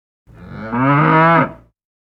Download Bull sound effect for free.
Bull